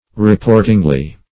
reportingly - definition of reportingly - synonyms, pronunciation, spelling from Free Dictionary Search Result for " reportingly" : The Collaborative International Dictionary of English v.0.48: Reportingly \Re*port"ing*ly\, adv. By report or common fame.